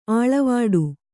♪ āḷavāḍu